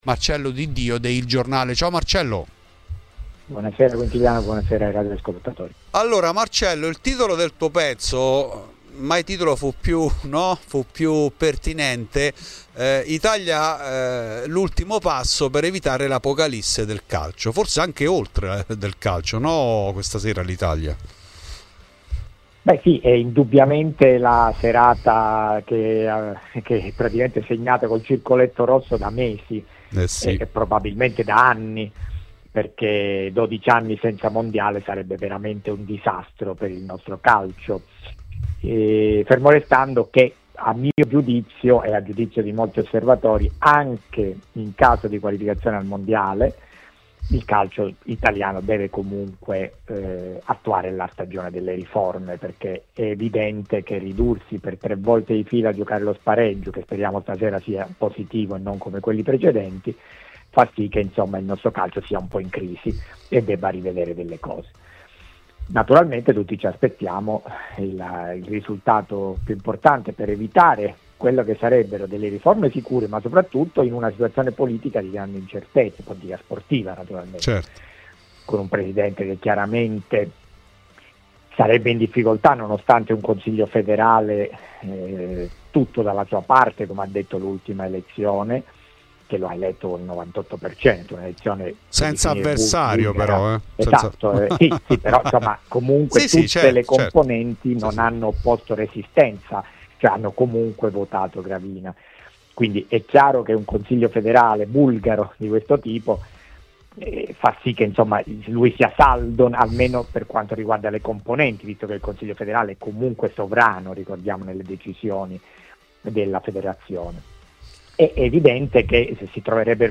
Ospite di Radio Bianconera